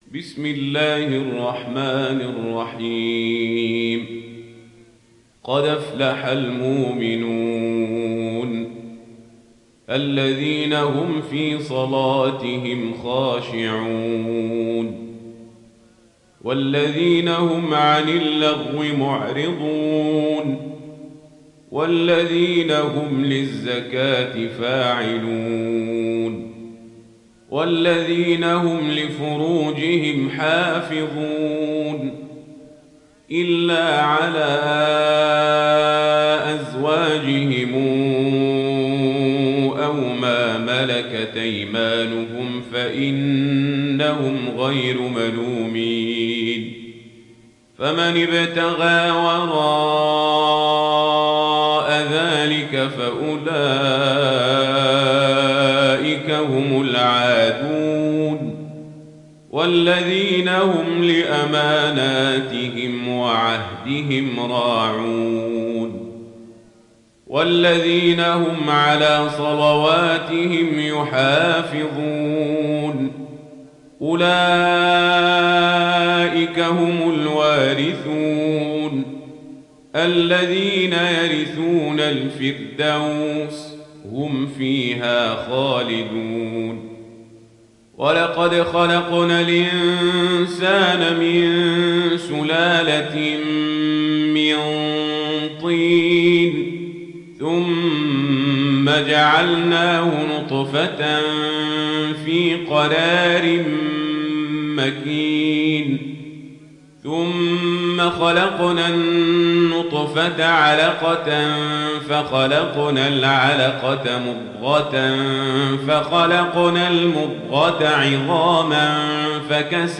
Riwayat Warsh